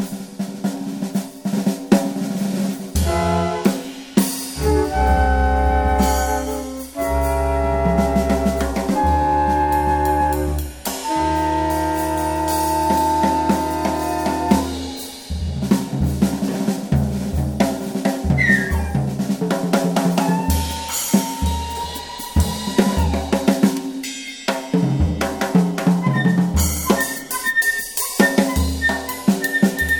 ens. voc. & instr.